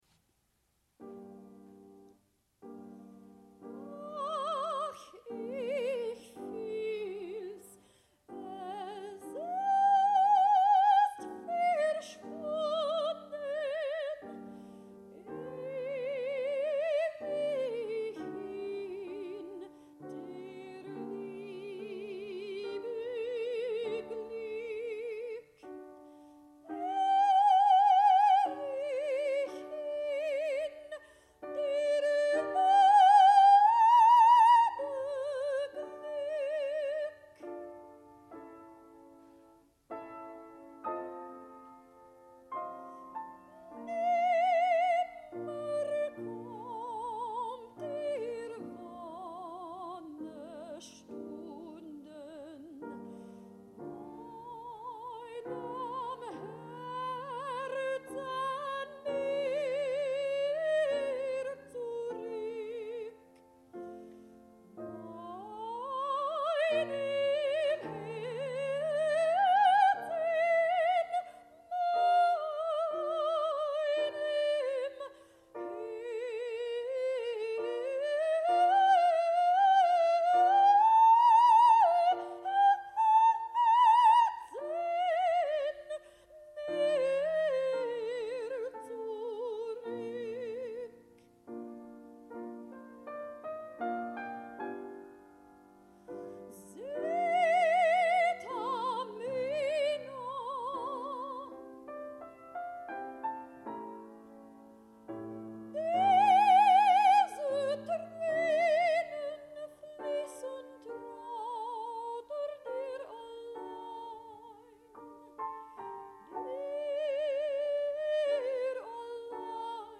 Opera/Classical